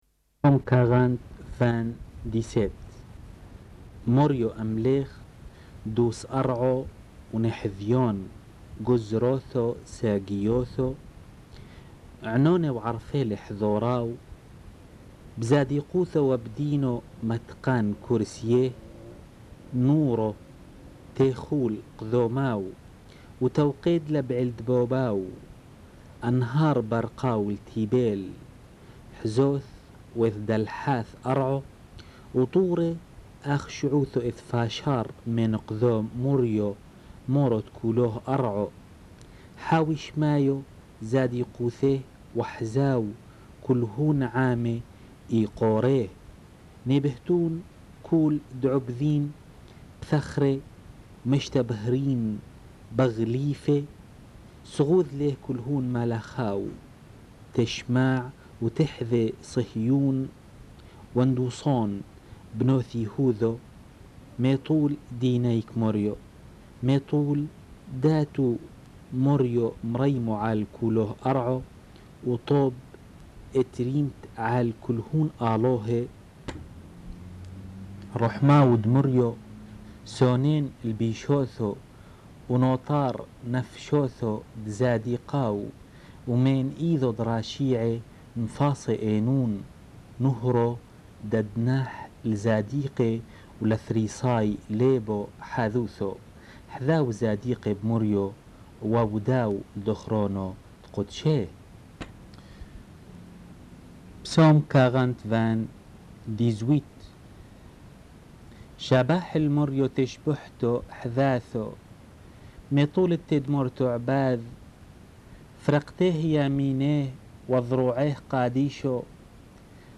Enregistrement de la lecture des Psaumes (version syriaque)